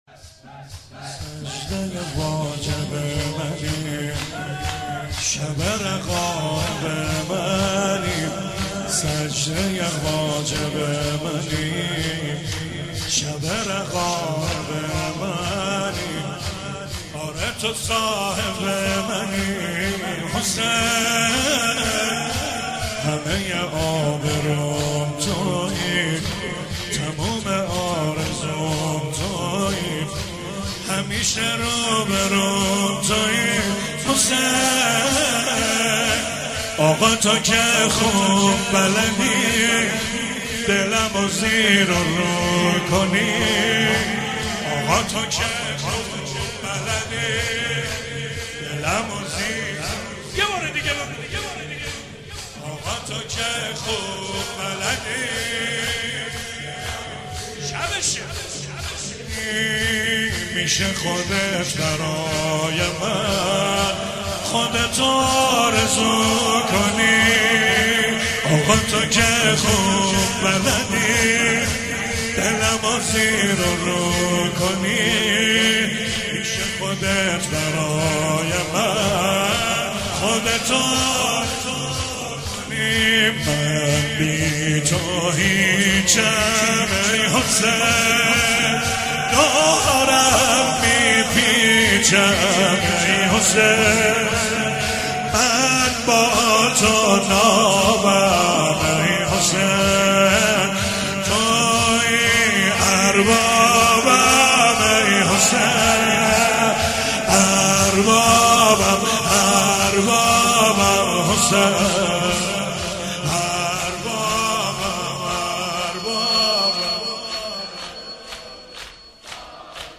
مولودی جدید
جشن میلاد امام علی (ع)